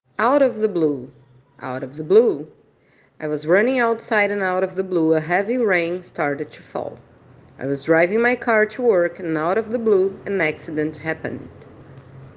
Attention to pronunciation ☝🏻🗣👂🏻